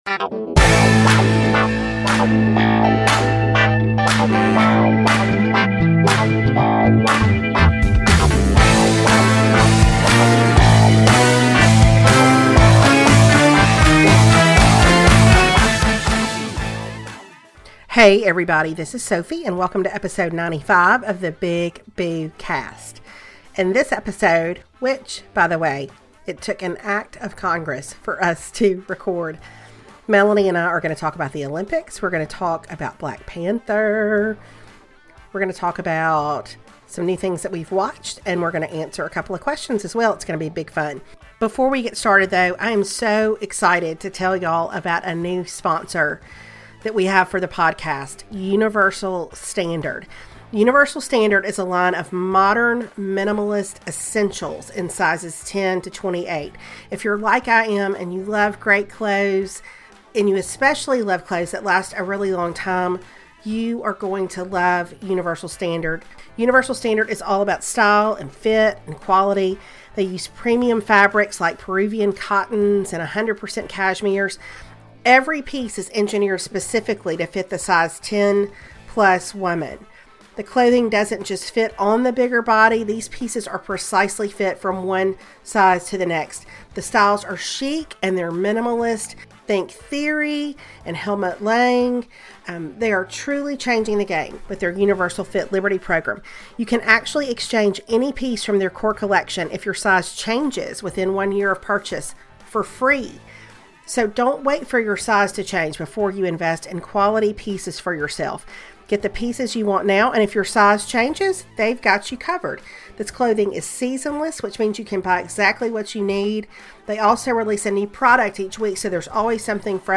Number of times I edited out the sound of throat-clearing: 784.